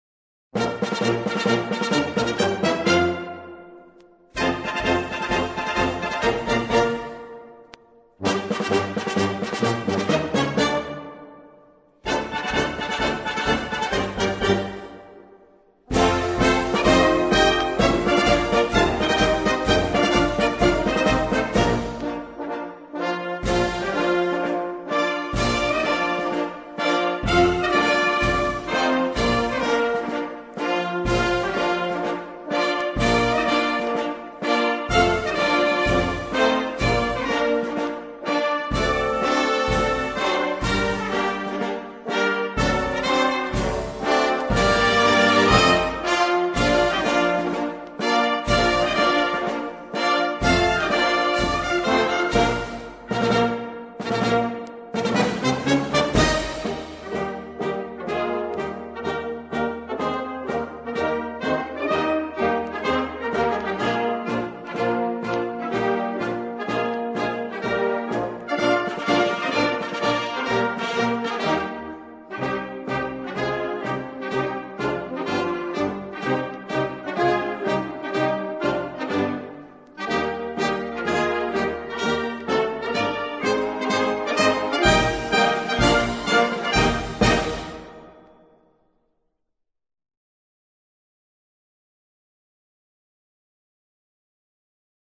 Inno_di_Mameli_-_(Inno_Nazionale)_-_Italia.mp3